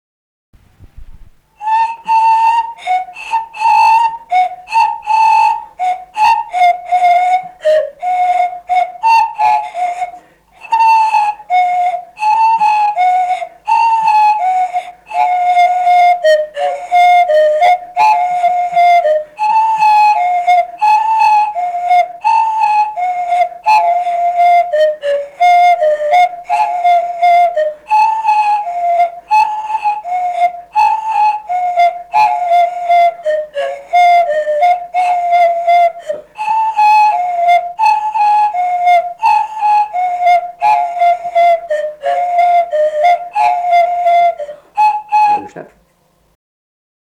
sutartinė
Atlikimo pubūdis instrumentinis
Instrumentas skudučiai